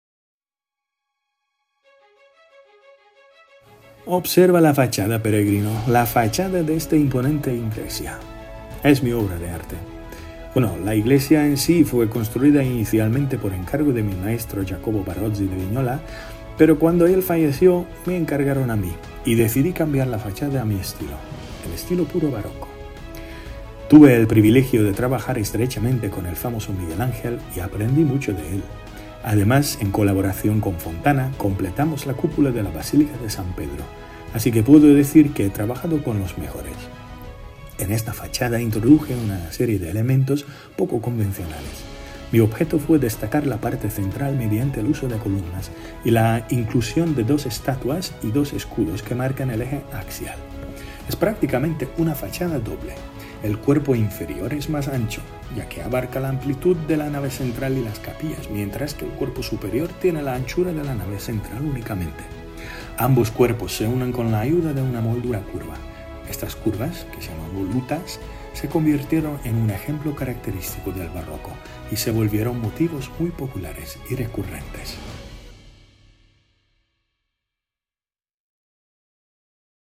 Giacomo della Porta habla de la fachada de la iglesia